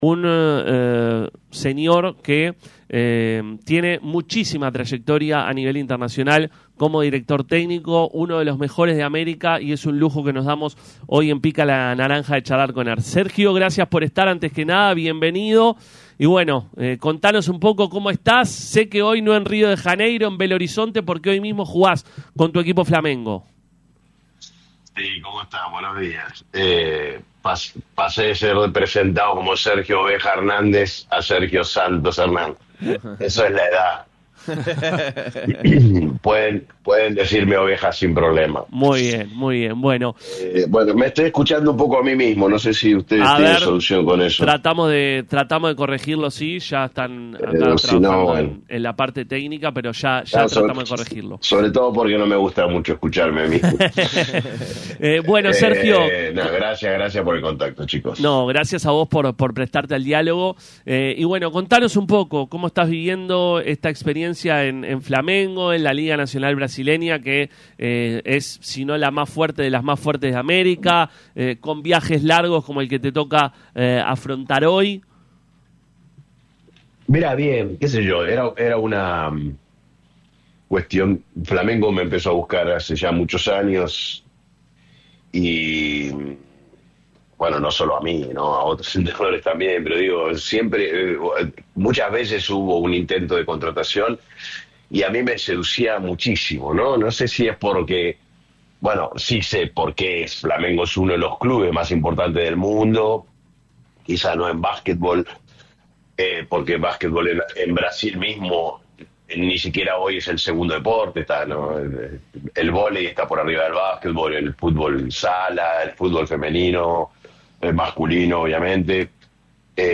El reconocido entrenador argentino “Oveja” Hernández pasó por los micrófonos de Radio Universal y habló con todo el equipo de Pica La Naranja.